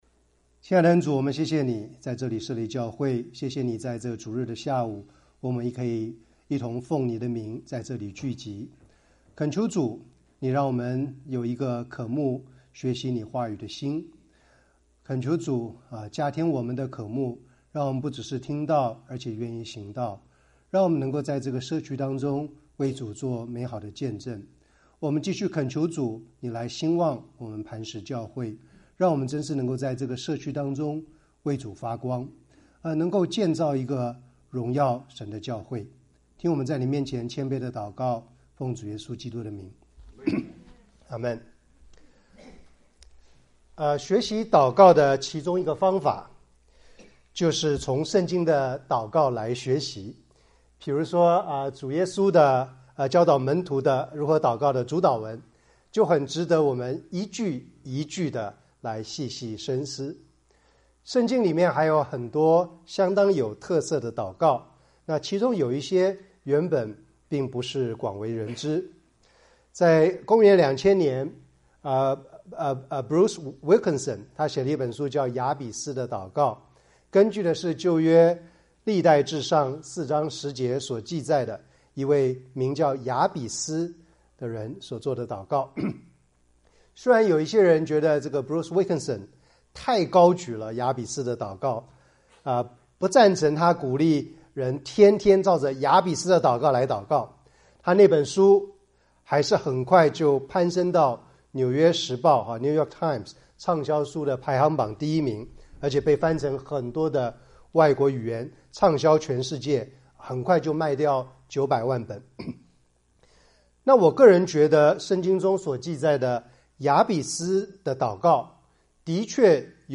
題目：”亞古珥向神求的兩件事” 講員：